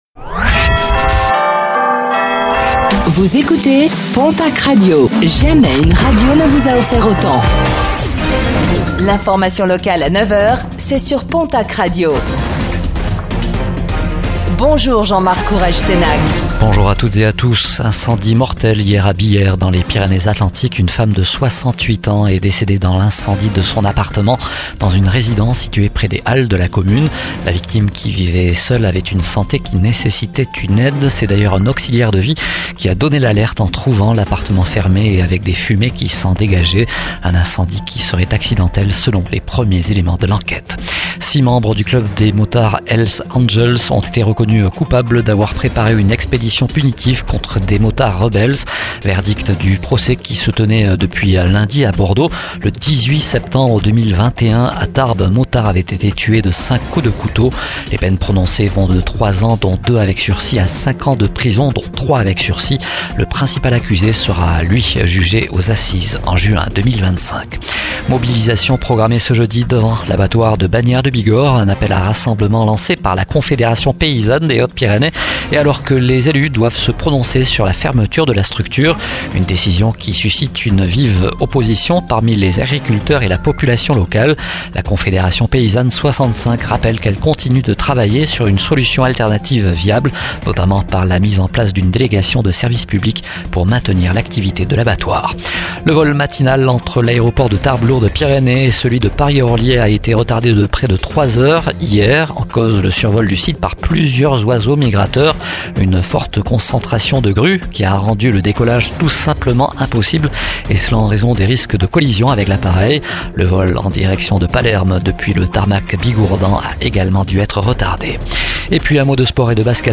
09:05 Écouter le podcast Télécharger le podcast Réécoutez le flash d'information locale de ce jeudi 24 octobre 2024